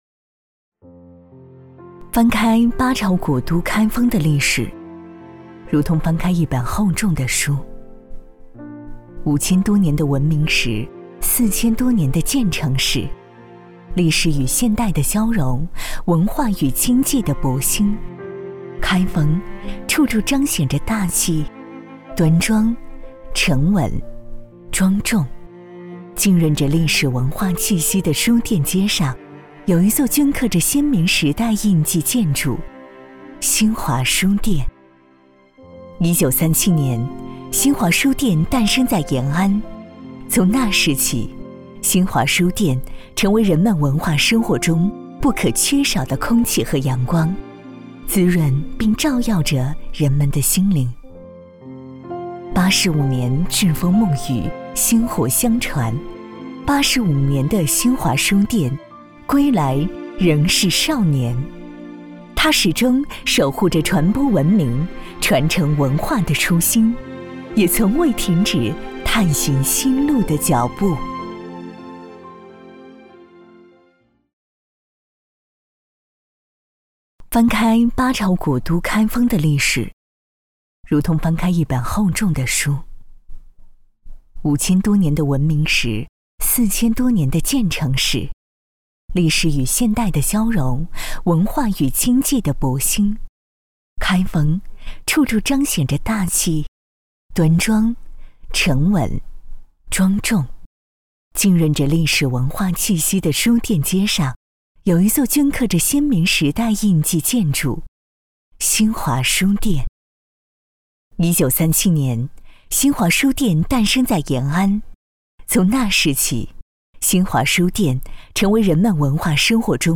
23女-温馨甜美素人
擅长：专题片 广告
特点：温柔 大气 甜美 磁性
风格:甜美配音